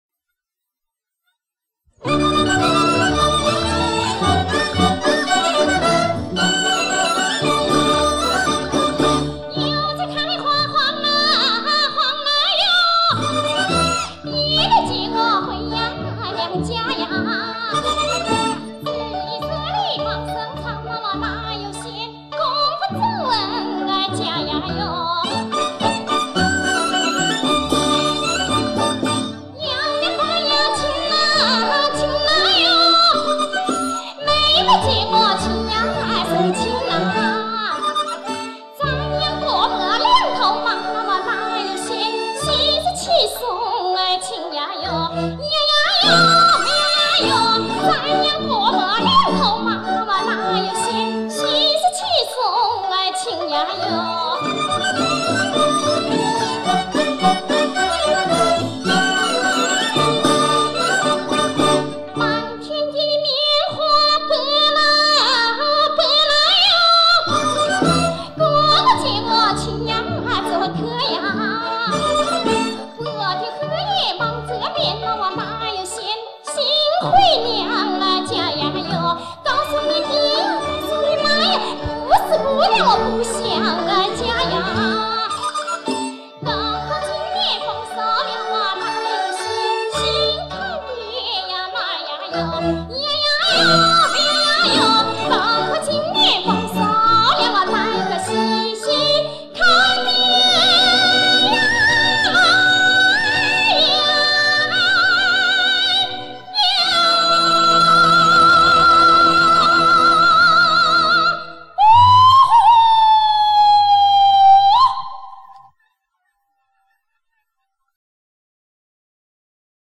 1988年她参加青歌赛时演唱蒋桂英的这首代表作，虽说模仿的痕迹还是比较重，但梦鸽的演唱还是很有烟火气的，令人感受出她在湖北地方文艺演出团体时候的声音味道，我个人认为原胜过后来拜了“名师”给带出来那个甜品款式。